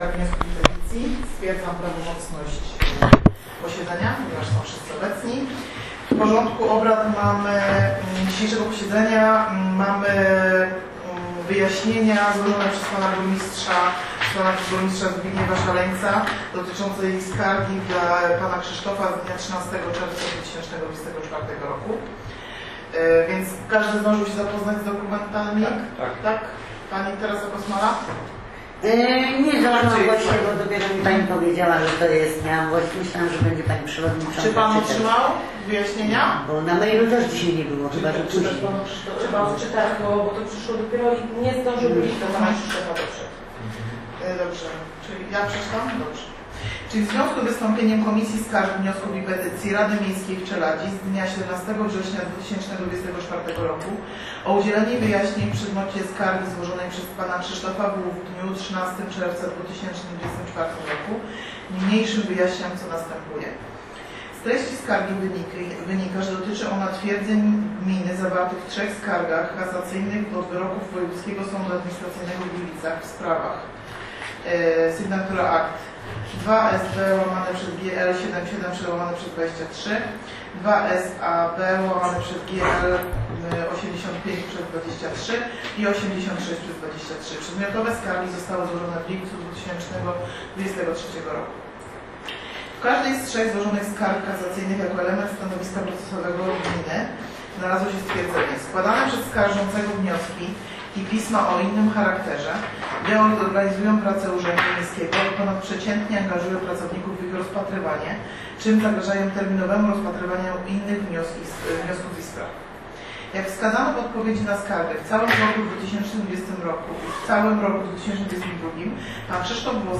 Udostępniony przez Plik dźwiękowy z posiedzenia Komisji, Skarg i Petycji z dnia 25.09.2024r. 7.11MB 2024-09-26